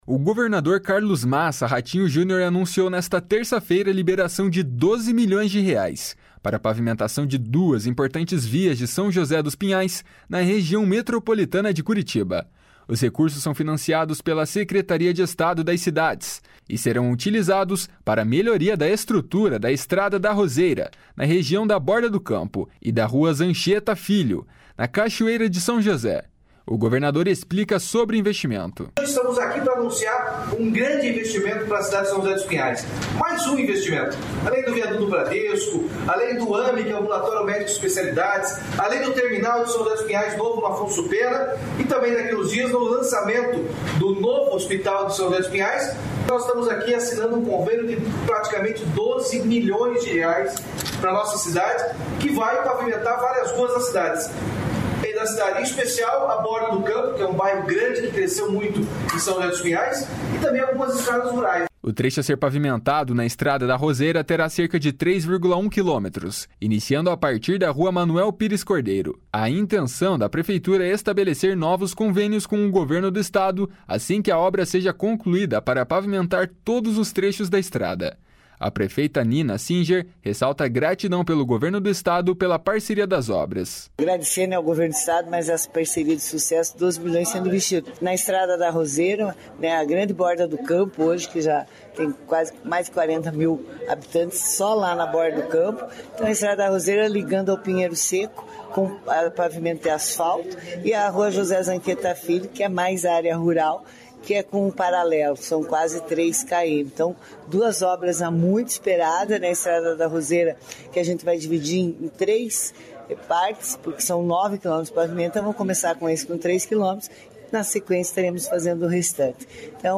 O governador explica sobre o investimento. // SONORA RATINHO JUNIOR //
A prefeita Nina Singer ressalta a gratidão pelo Governo do Estado pela parceria das obras.